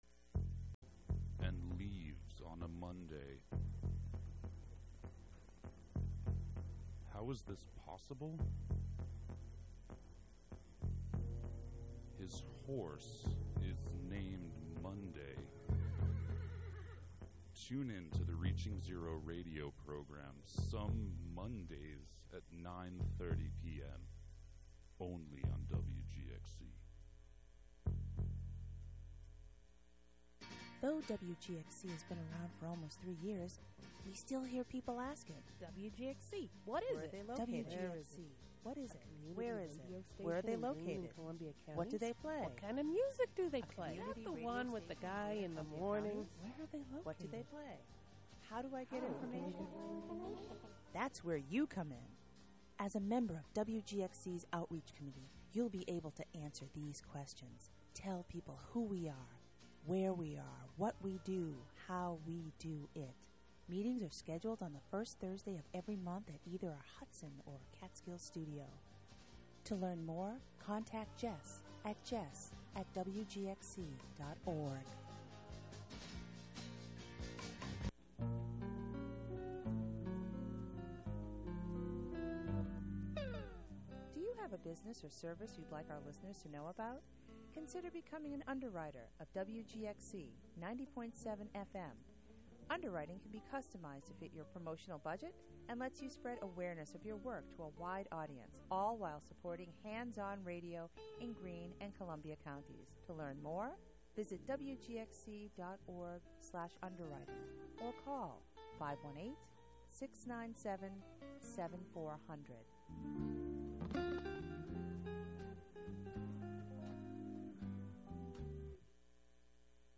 Discussion about the upcoming second annual block party sponsored by the Greater Hudson Promise Neighborhood and the Staley B. Keith Social Justice Center. Students from the Social Justice Leadership Academy stop by the studio, plus talks about "the Decision."